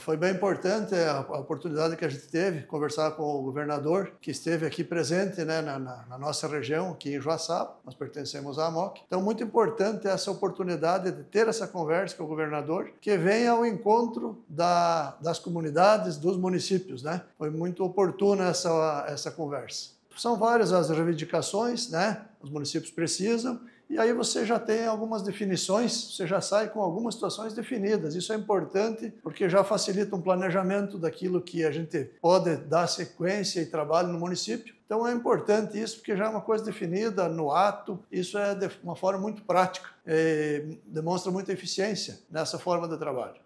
SONORA – SC Levada a Sério: prefeito de Água Doce destaca importância de reunião com governador
Após a conversa individual com o governador Jorginho Mello, o prefeito de Água Doce, Giovani Luiz Brandalise, destacou a importância de momentos como esse com o governador: